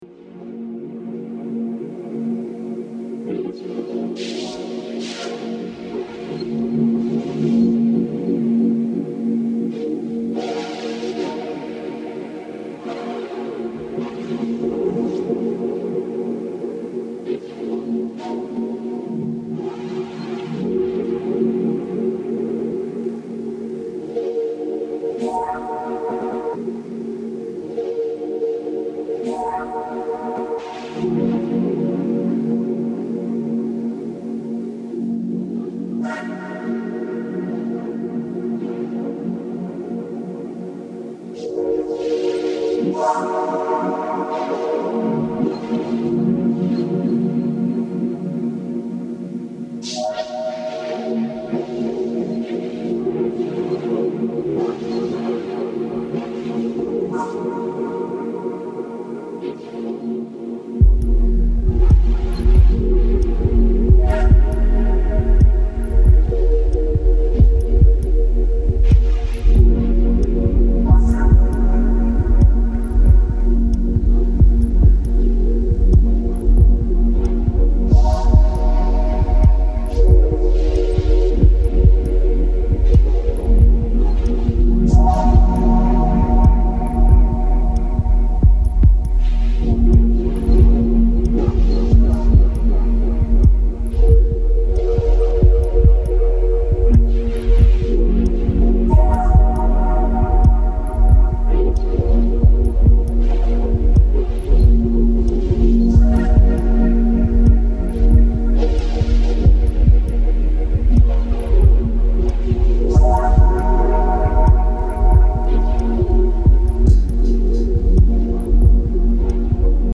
ダブ・テクノの新しい潮流を感じさせる一枚です。